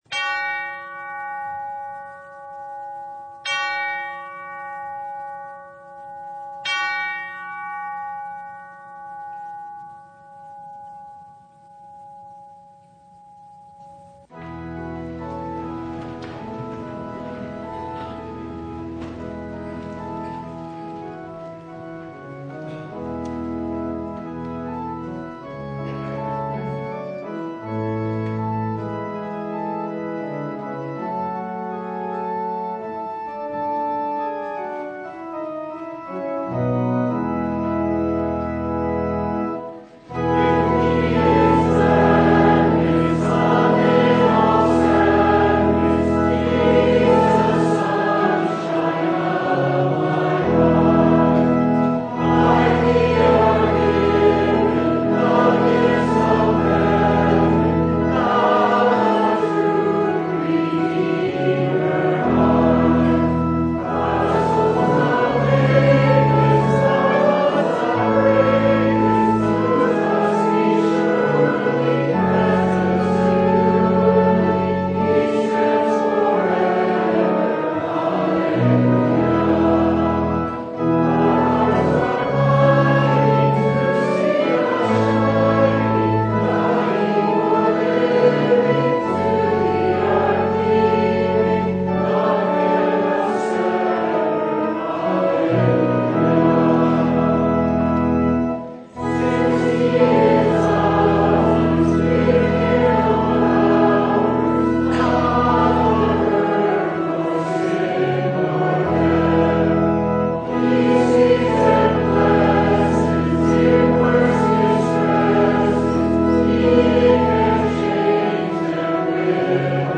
Mark 4:35-41 Service Type: Sunday Jesus said